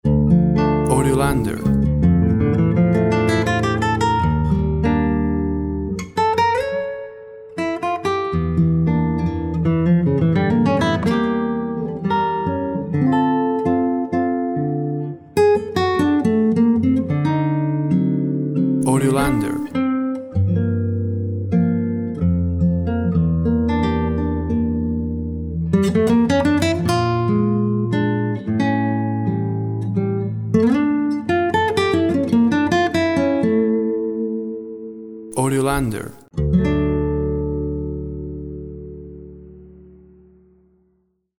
Acoustic Nylon Guitar Solo. Classical, lyrical.